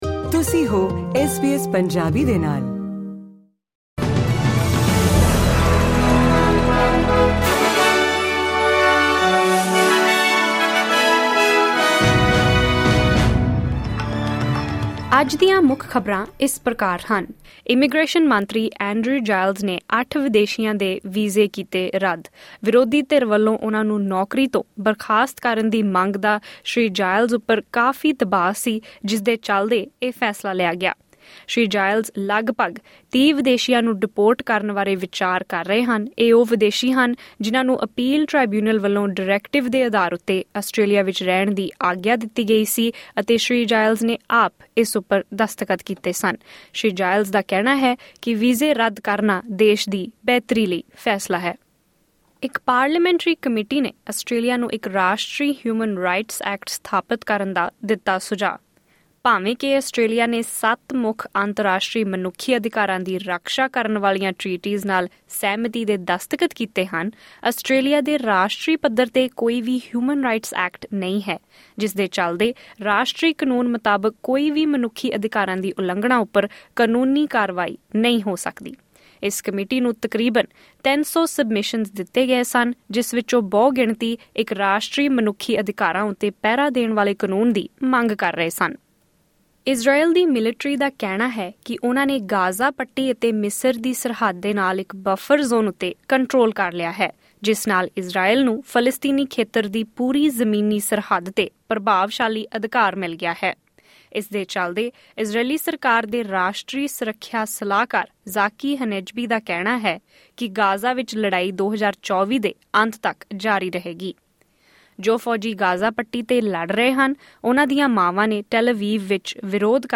ਐਸ ਬੀ ਐਸ ਪੰਜਾਬੀ ਤੋਂ ਆਸਟ੍ਰੇਲੀਆ ਦੀਆਂ ਮੁੱਖ ਖ਼ਬਰਾਂ: 30 ਮਈ, 2024